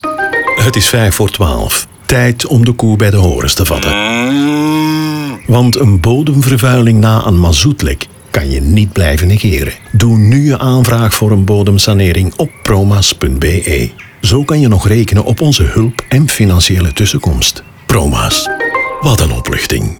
240507_Promaz_NL20s_Radio_02.mp3